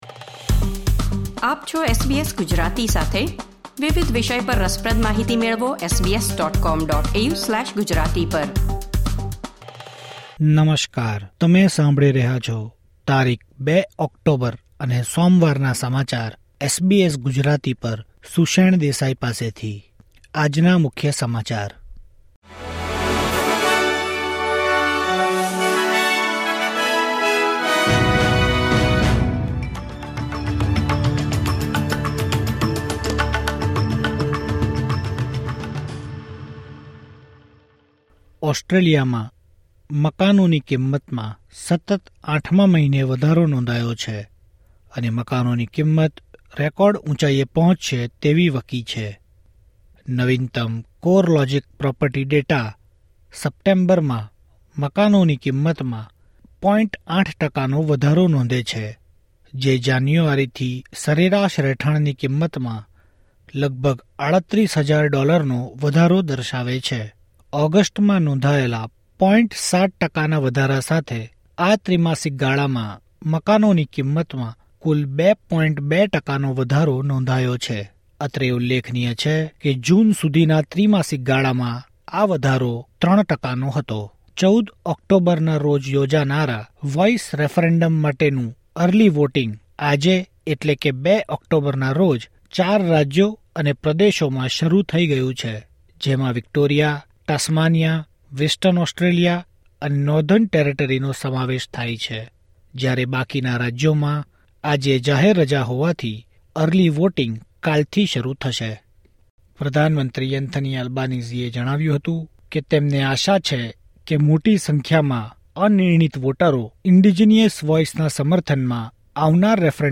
SBS Gujarati News Bulletin 2 October 2023